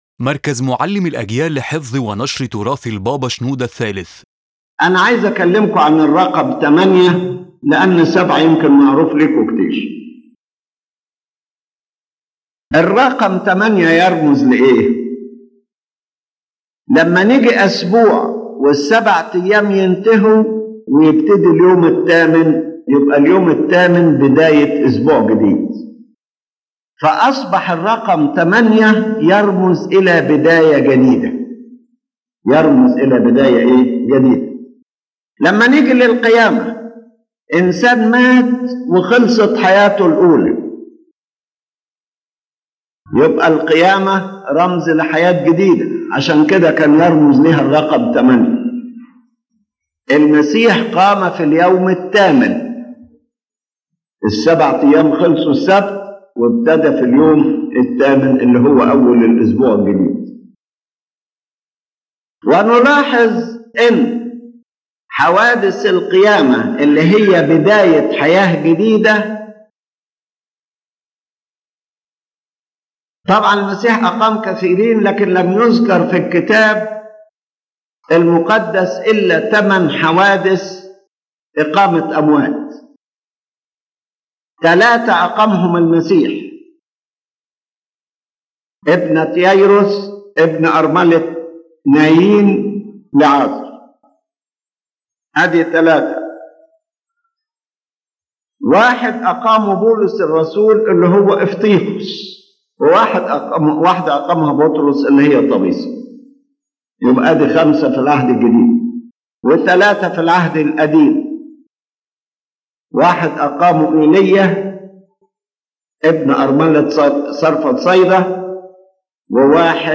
His Holiness Pope Shenouda III explains that the eighth day comes after the completion of the seven, expressing the start of a new week and a new covenant and life; therefore the number is associated with the resurrection and the new life given in Christ.